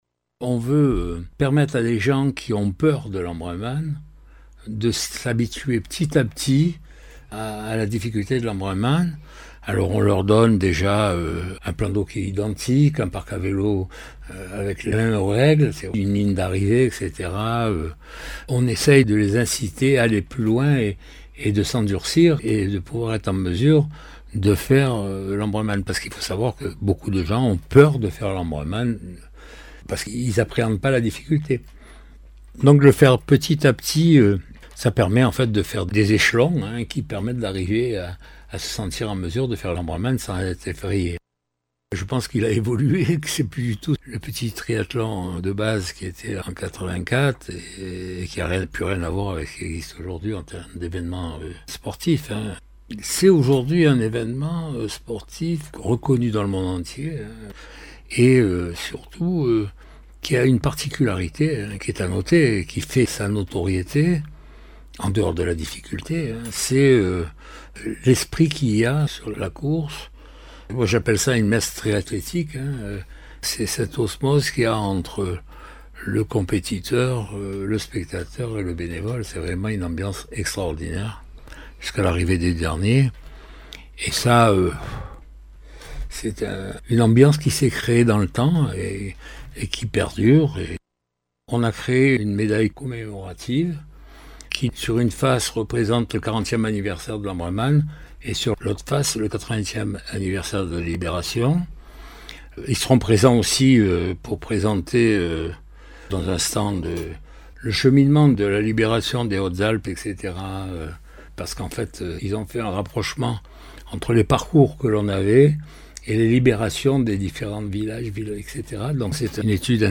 Interviews
• Avant la course :